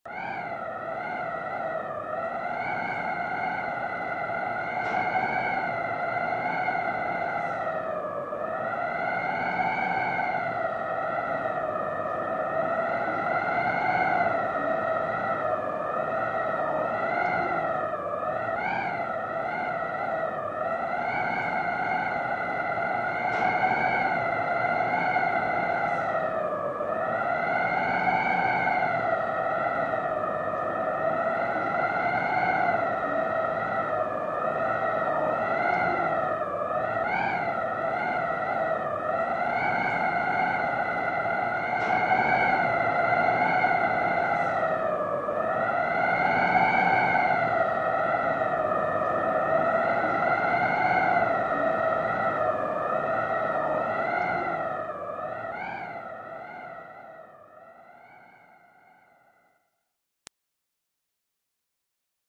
BRISA DE VIENTO
Ambient sound effects
brisa_de_viento.mp3